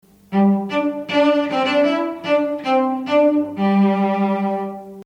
Instrumente | Cello – Musikschule Region Baden
cello.mp3